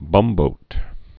(bŭmbōt)